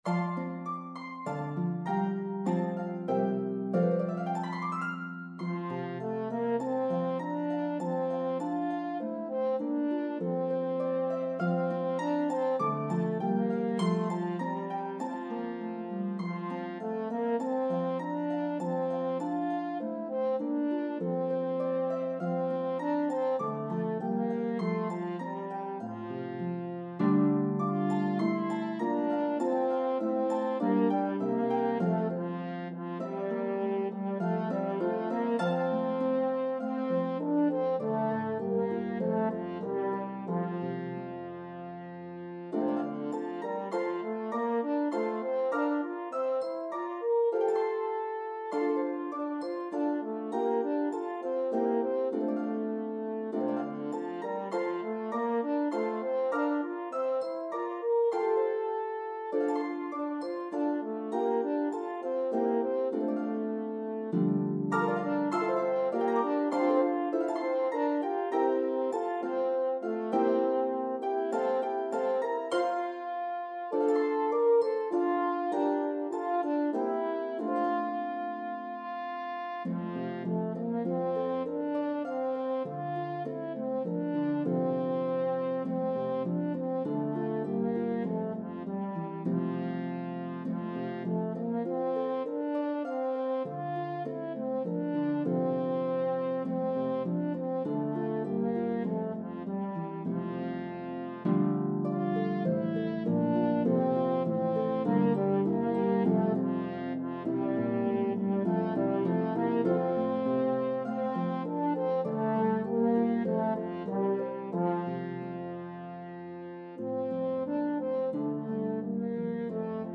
The exuberant French Carol